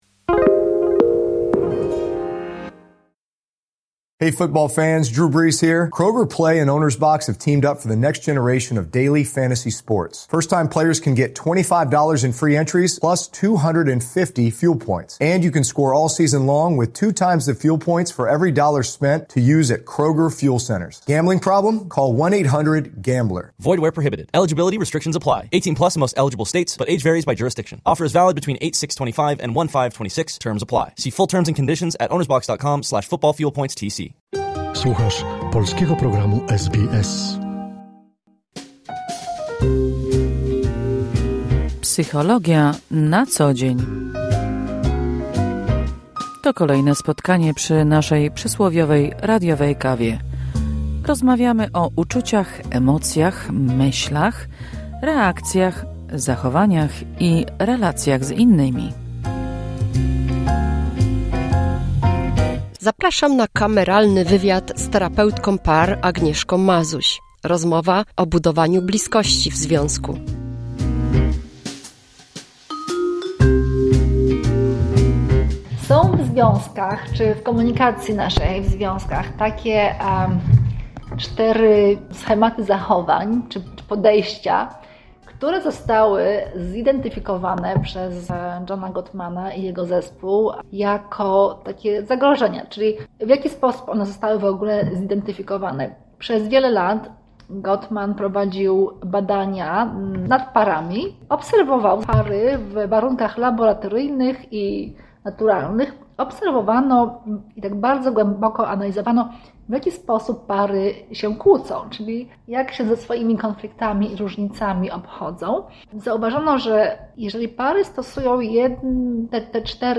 Kolejne spotkanie przy przysłowiowej radiowej kawie z cyklu "Psychologia na co dzień".